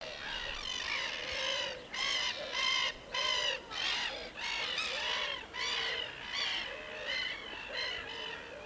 Gaviota Capucho Café (Chroicocephalus maculipennis)
Nombre en inglés: Brown-hooded Gull
Localización detallada: Club de Pesca y Turismo de Saavedra
Condición: Silvestre
Certeza: Observada, Vocalización Grabada